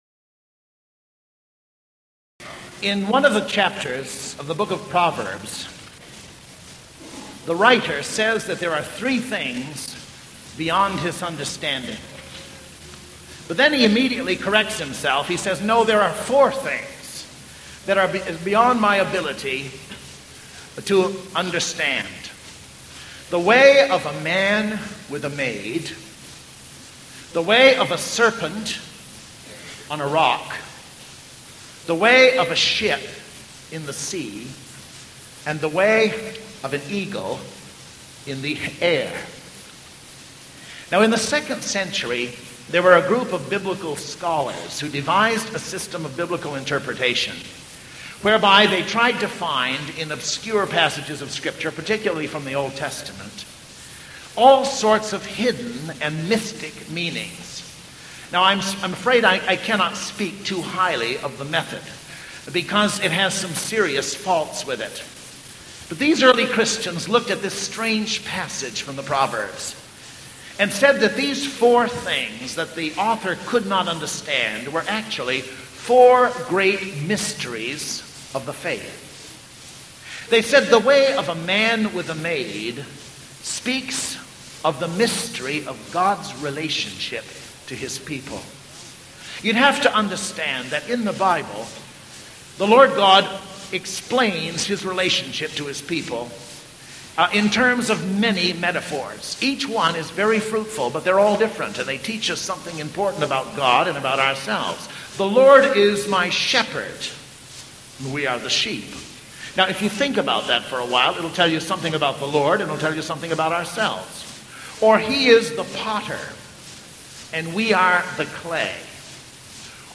I made an MP3 file from the cassette tape I bought at the conference. I had to do some slight editing when I flipped the tape.
Remember, that this was recorded in the early ’80’s.
eagle-sermon-edited.mp3